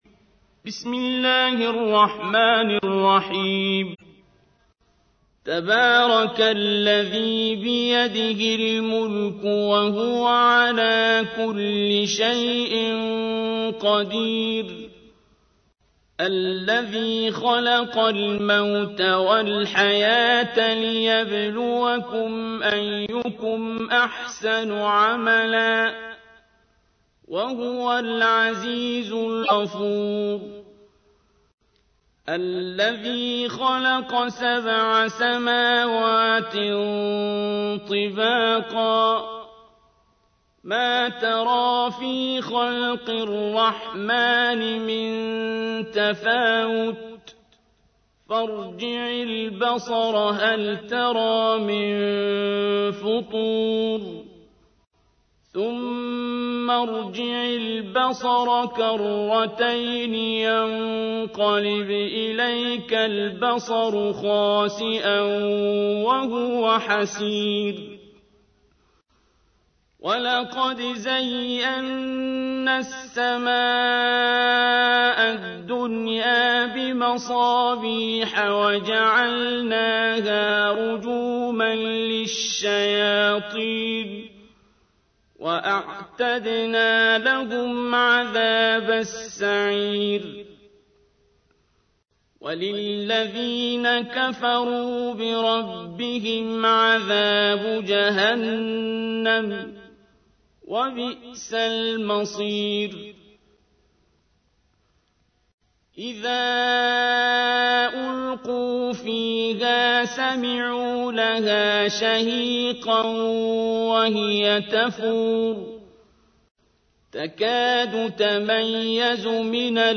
تحميل : 67. سورة الملك / القارئ عبد الباسط عبد الصمد / القرآن الكريم / موقع يا حسين